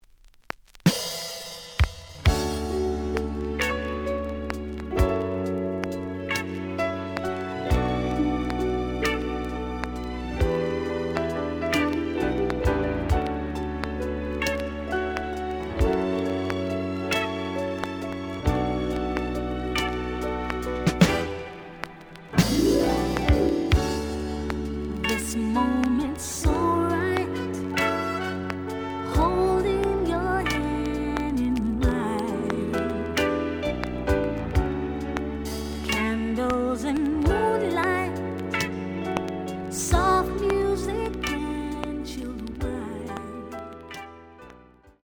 試聴は実際のレコードから録音しています。
●Genre: Disco
●Record Grading: VG (B面に傷によるクリックノイズあり。傷は多いが、A面のプレイはまずまず。)